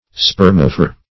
Search Result for " spermaphore" : The Collaborative International Dictionary of English v.0.48: Spermaphore \Sper"ma*phore\, n. [Gr.